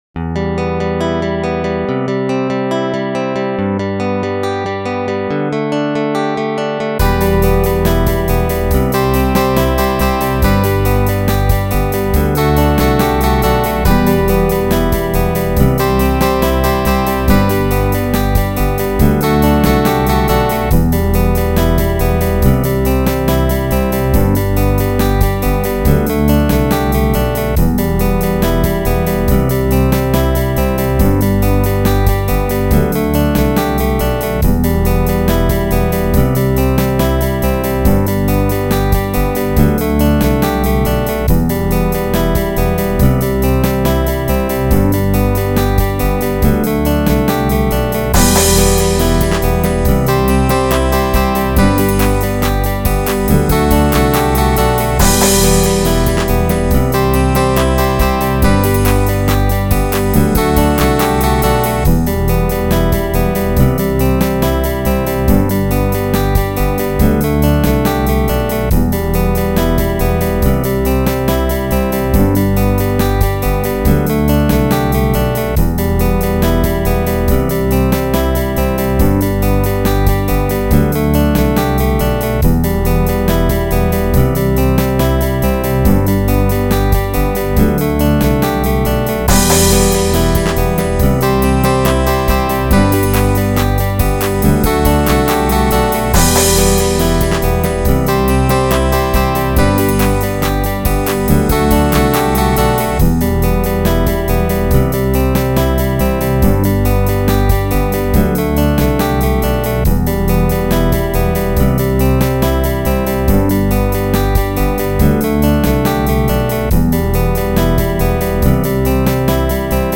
Вот ссылочка на минус:
Сперва не понял:) Это не рэп, но довольно занятно!
Гитара живая.